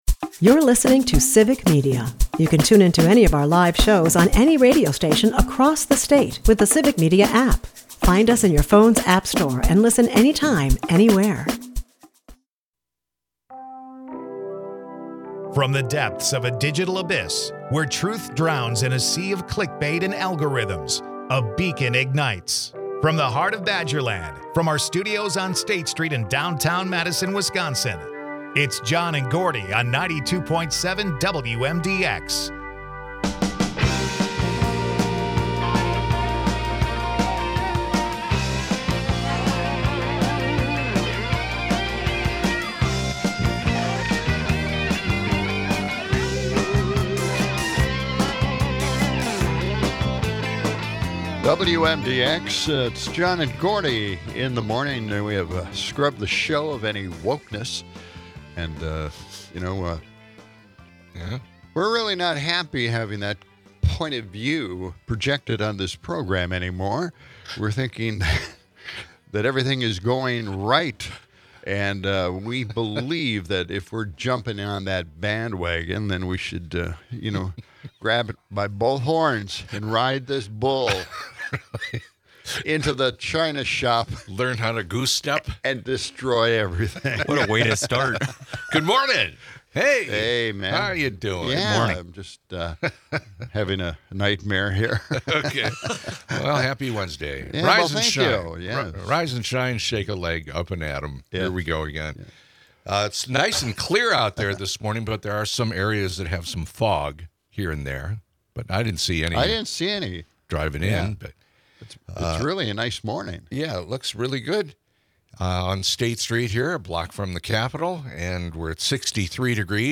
With a sprinkle of celebrity charm, Samuel L. Jackson's voice booms in an ad parodying his iconic movie line, advocating for wind energy. Meanwhile, a spirited debate on condiments reveals the Midwest's love for flavor.